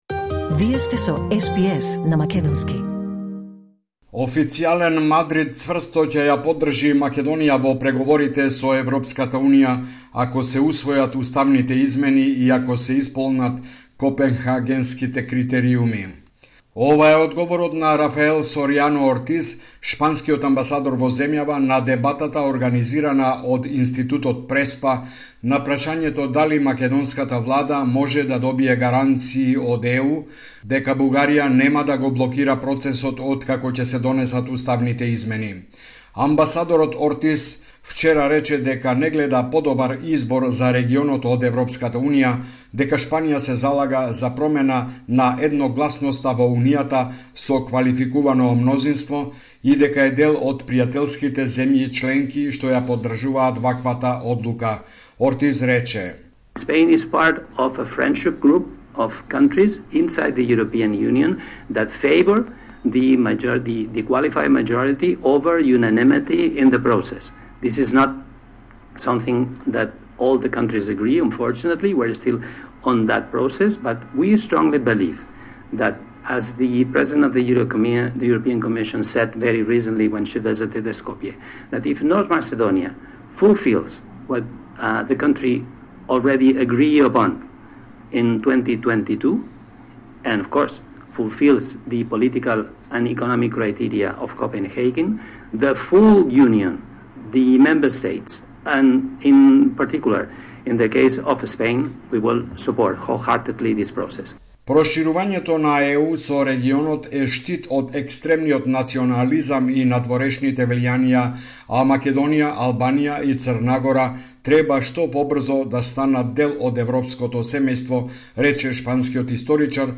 Извештај од Македонија 28 октомври 2025